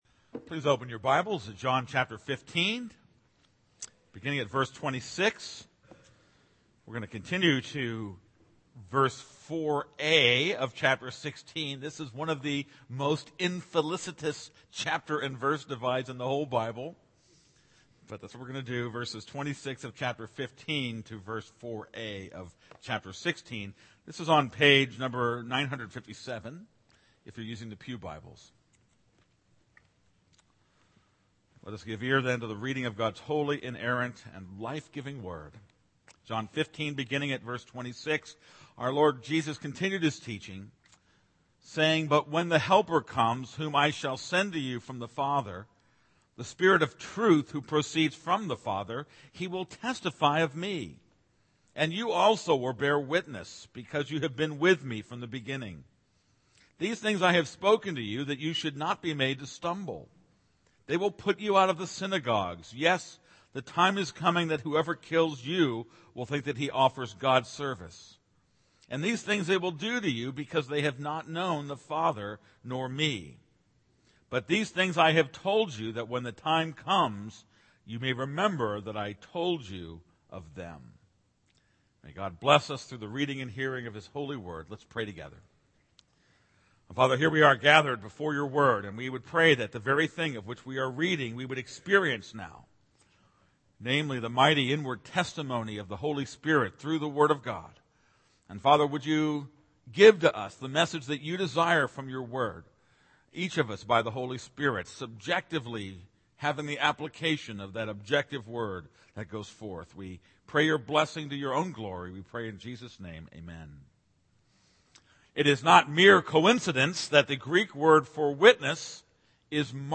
This is a sermon on John 15:26-16:4.